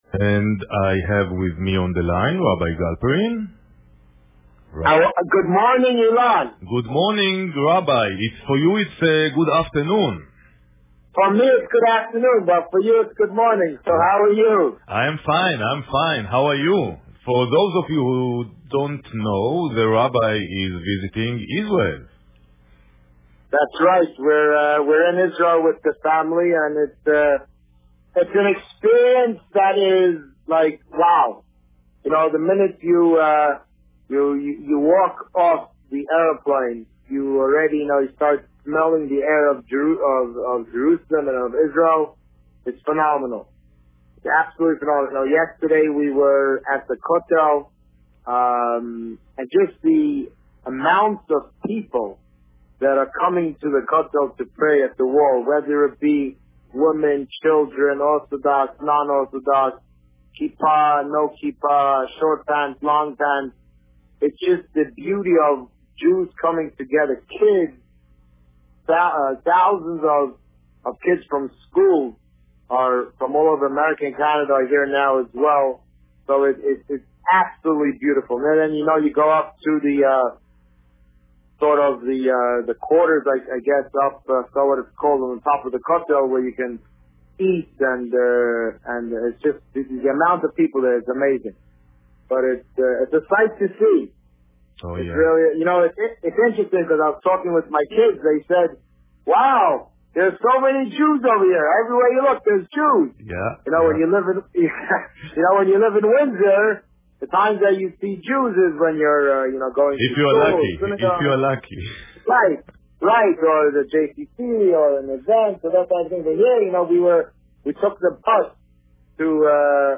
The Rabbi on Radio
This week's interview features the rabbi speaking about his family vacation, live from Israel.